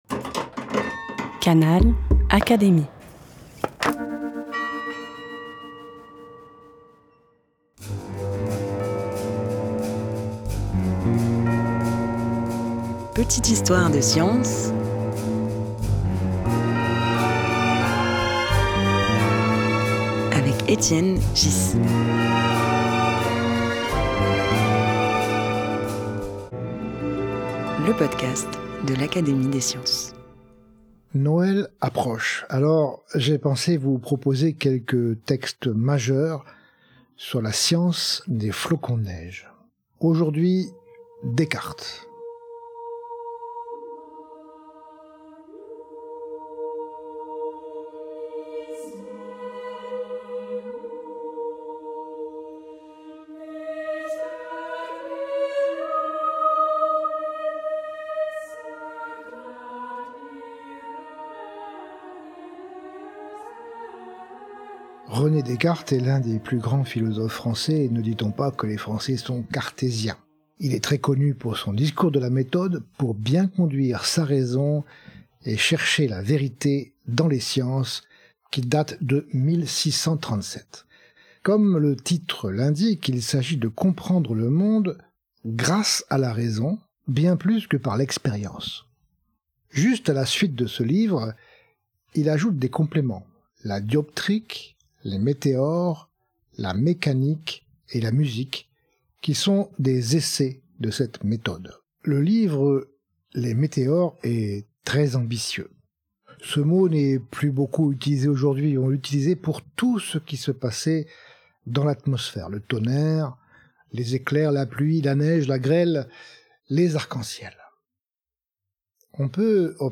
À travers cette lecture commentée, Étienne Ghys met en lumière les tâtonnements de la science naissante : hypothèses audacieuses, raisonnements complexes, erreurs assumées.